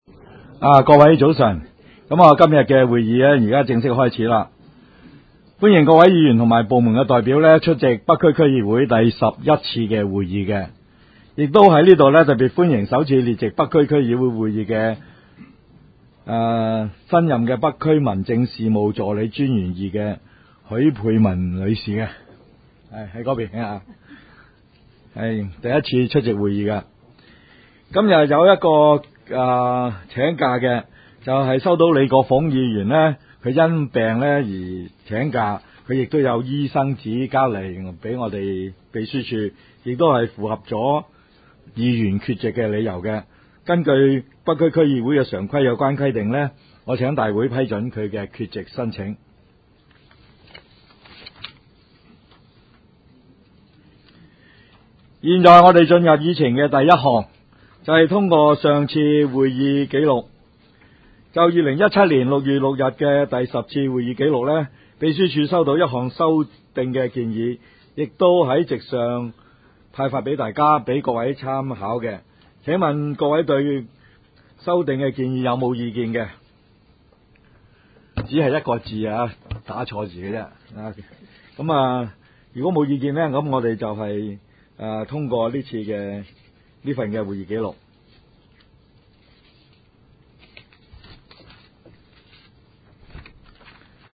区议会大会的录音记录